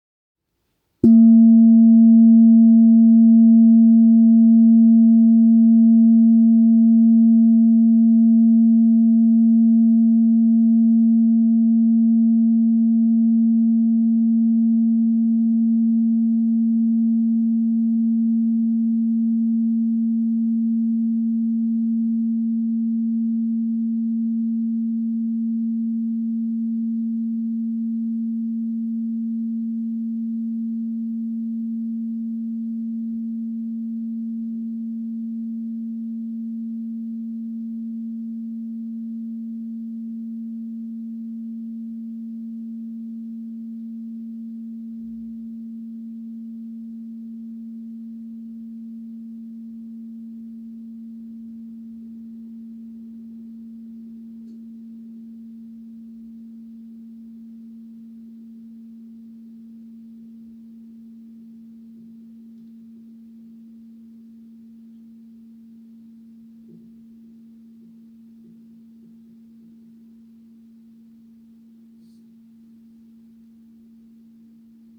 de meditatiebel [klik hier] regelmatig worden aangeslagen.
grote-klankschaal-AudioTrimmer.com_-1.mp3